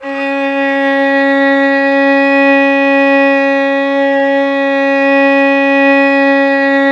Index of /90_sSampleCDs/Roland L-CD702/VOL-1/STR_Violin 4 nv/STR_Vln4 _ marc
STR VLN BO04.wav